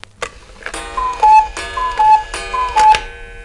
Cuckoo Clock Sound Effect
Download a high-quality cuckoo clock sound effect.
cuckoo-clock.mp3